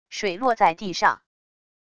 水落在地上wav音频